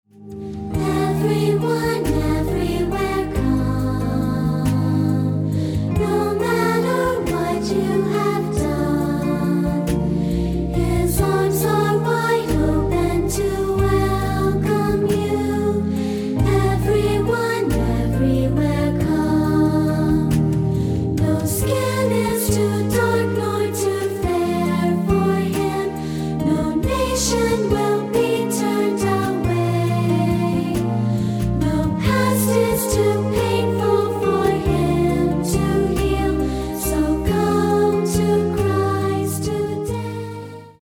A lovely song
short solo